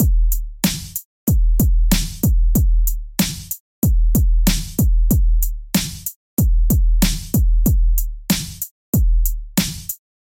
标签： 94 bpm RnB Loops Drum Loops 1.72 MB wav Key : Unknown
声道立体声